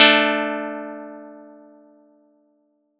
Audacity_pluck_6_14.wav